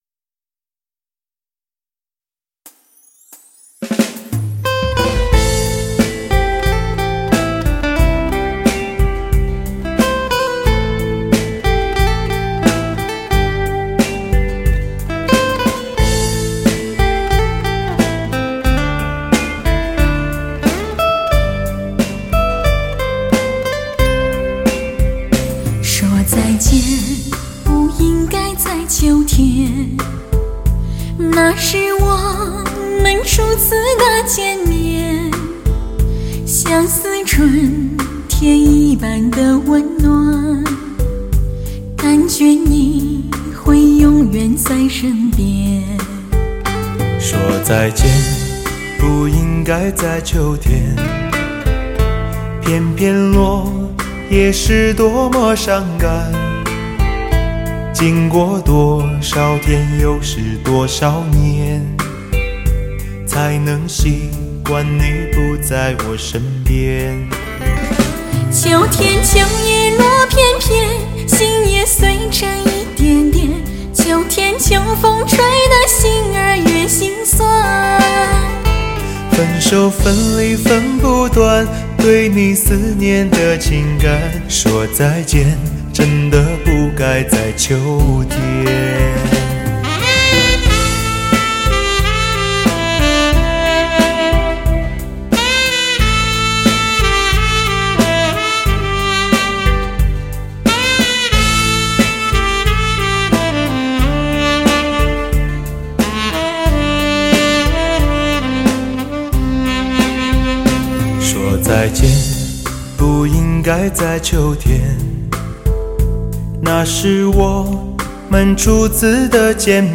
全碟乐器搭配天衣无缝